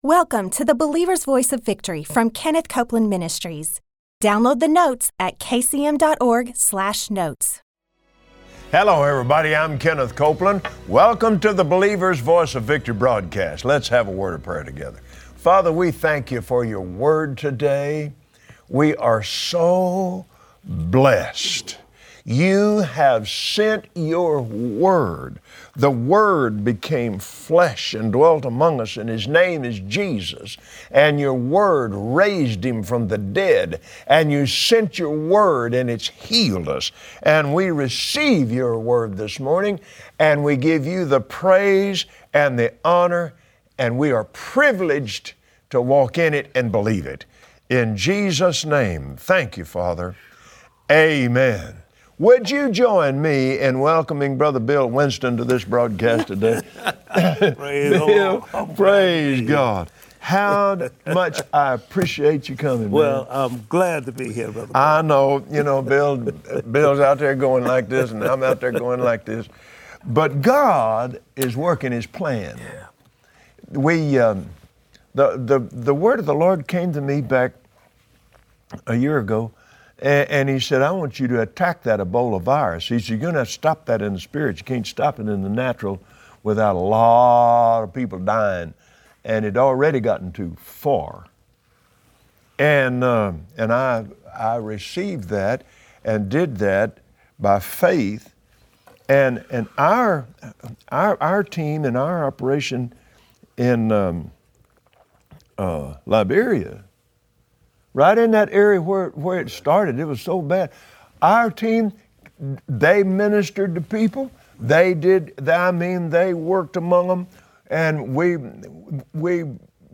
Today Kenneth Copeland and his special guest